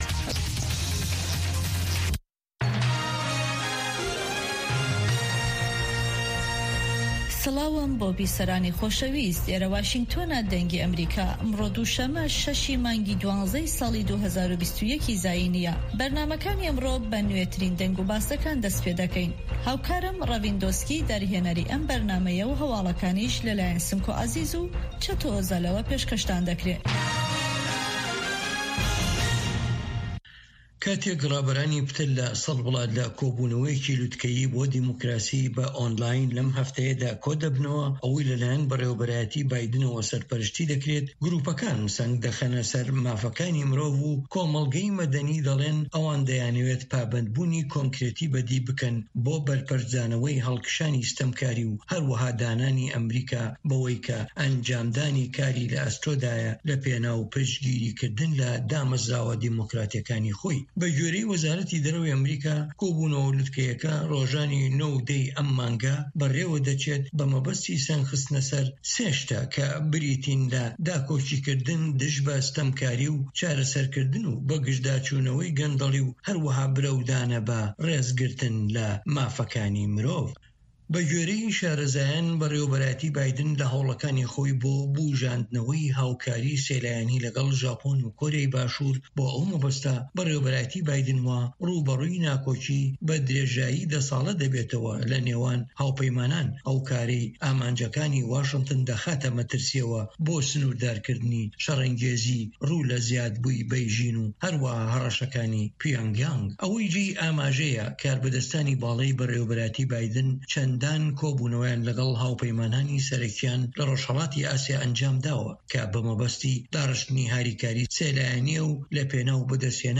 هەواڵەکانی 1 ی پاش نیوەڕۆ